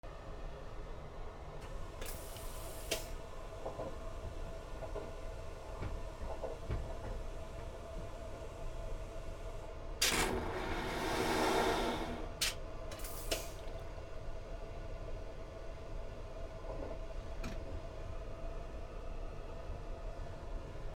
特急電車 トイレを流す
/ E｜乗り物 / E-60 ｜電車・駅